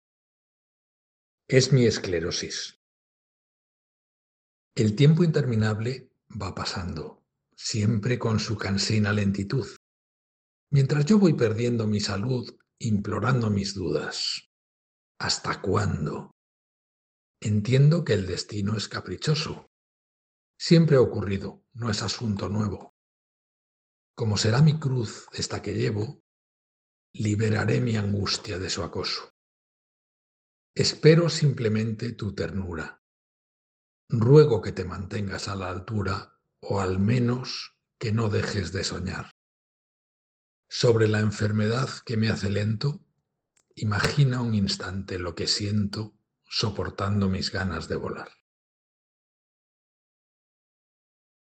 Versión audiolibro-digital de Baile de Pingüinos por la Esclerosis Múltiple